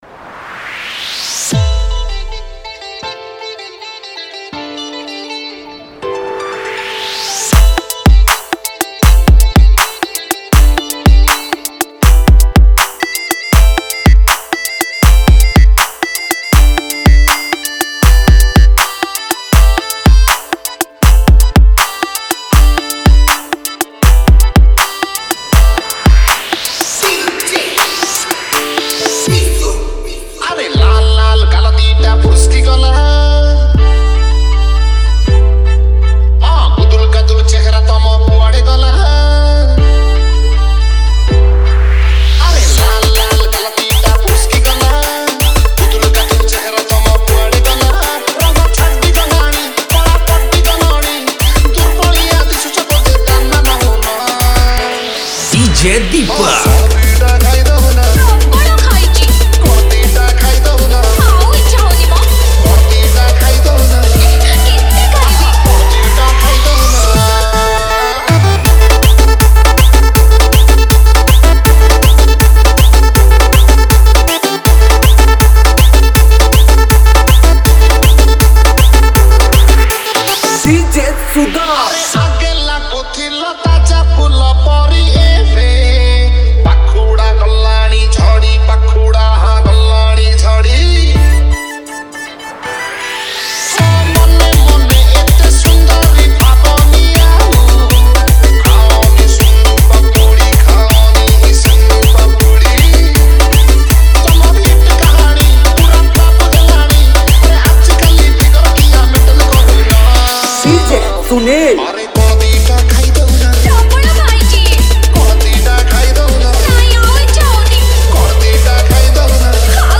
Category : Trending Remix Song